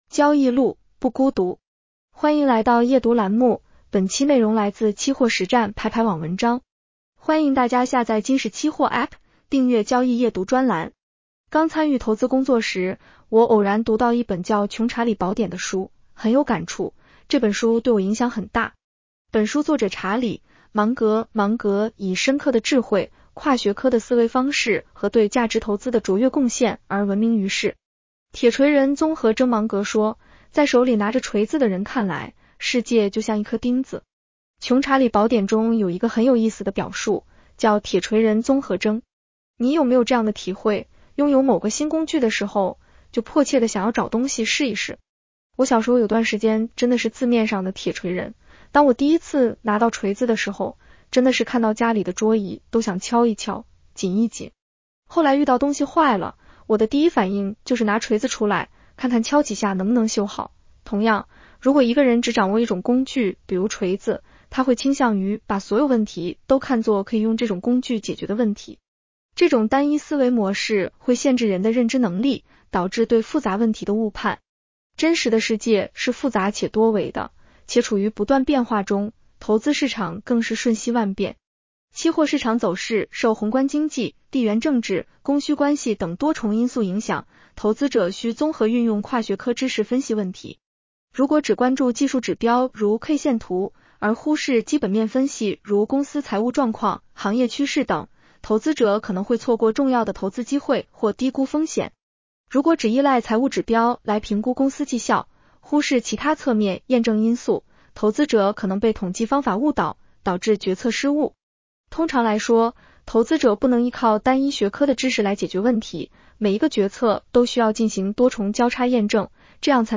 女声普通话版 下载mp3 刚参与投资工作时，我偶然读到一本叫《穷查理宝典》的书，很有感触，这本书对我影响很大。